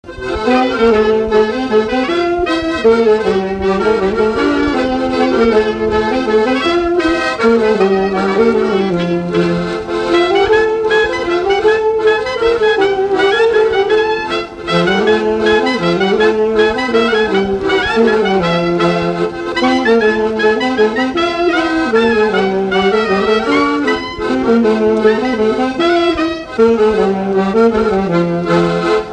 Mazurka
Chaillé-sous-les-Ormeaux
Chants brefs - A danser
danse : mazurka